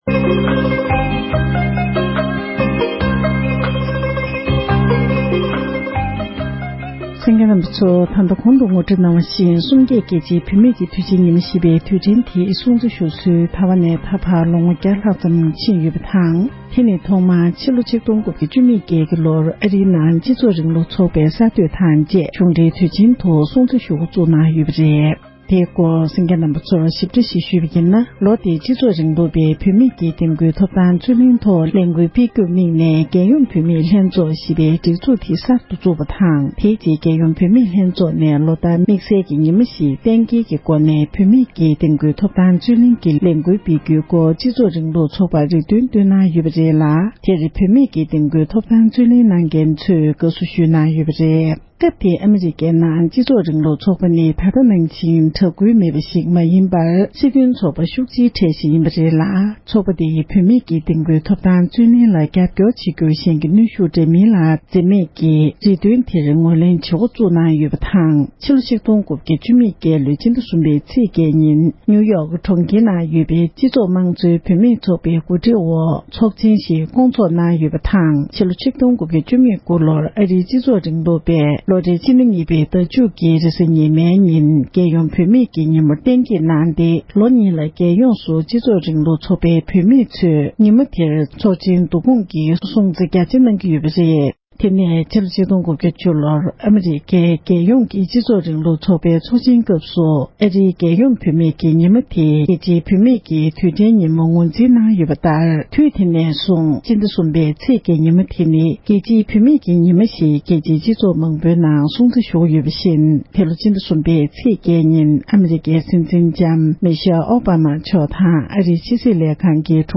འབྲེལ་ཡོད་མི་སྣར་བཀའ་འདྲི་ཞུས་པར་གསན་རོགས༎